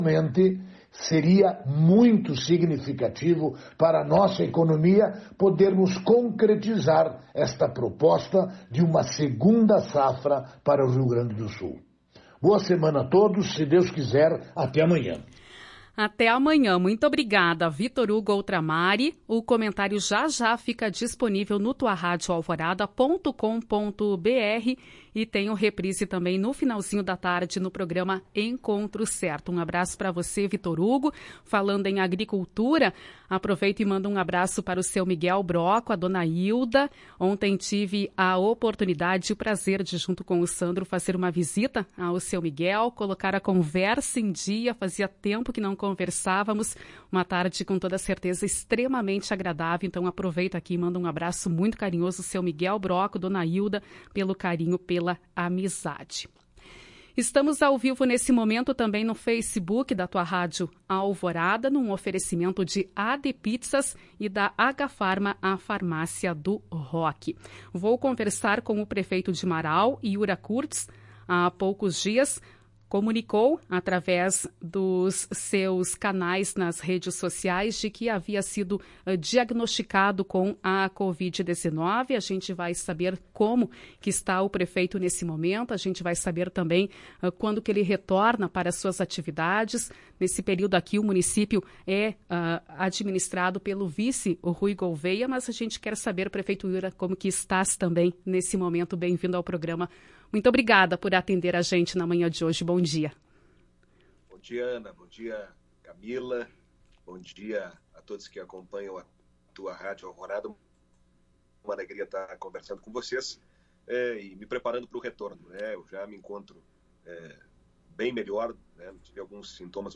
Em entrevista para a emissora, na manhã desta segunda-feira, 07/06, o gestor relatou ter sentido alguns sintomas mais fortes que outros, como as dores no corpo e a febre.